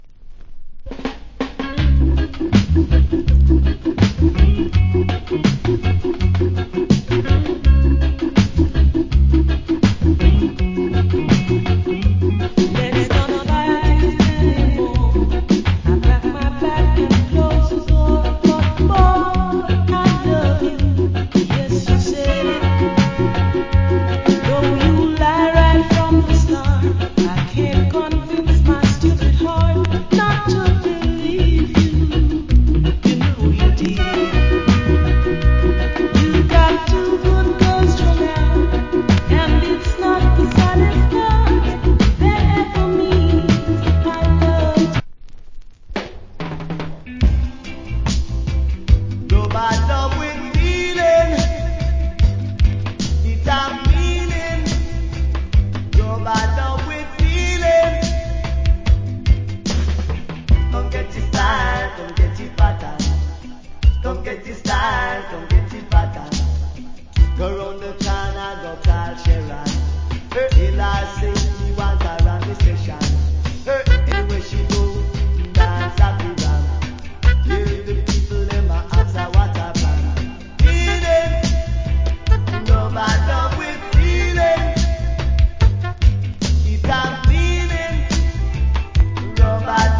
Cool Female Reggae Vocal.